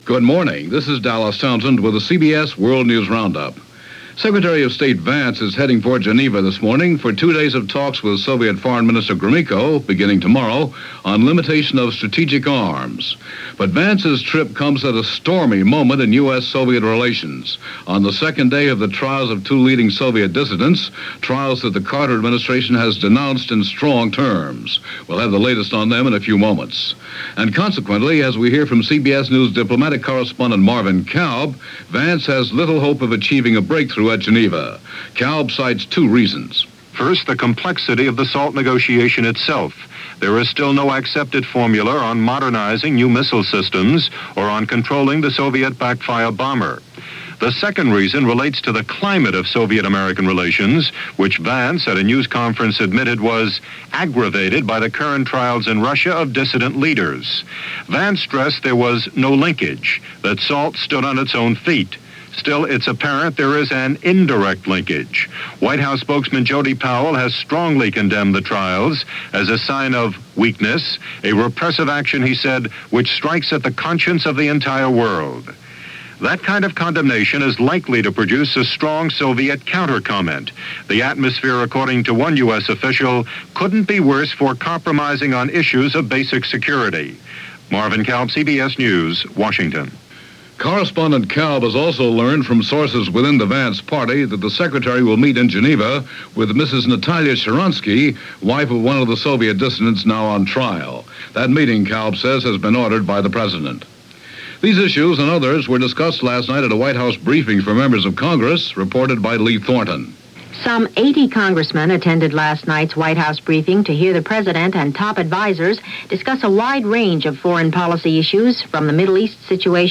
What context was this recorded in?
And that’s a small slice of what happened, this July 11, 1978 as reported by The CBS World News Roundup.